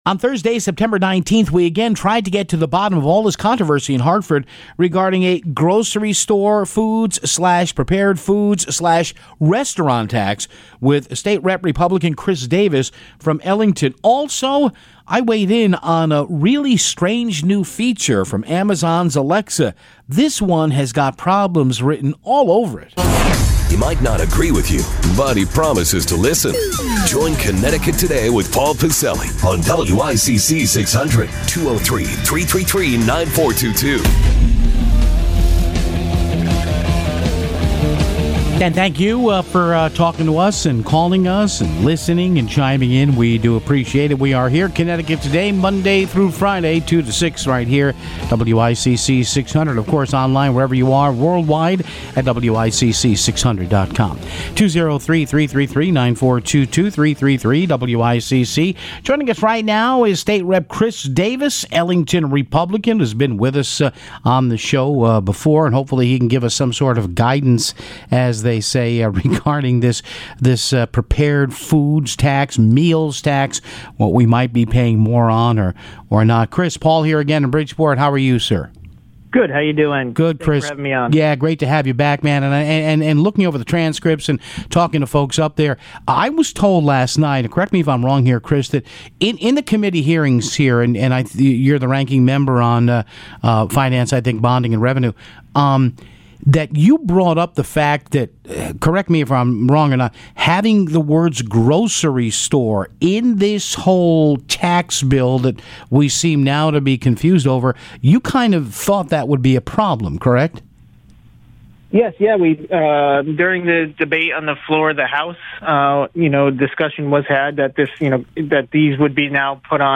a conversation about the grocery tax with State Rep. Chris Davis.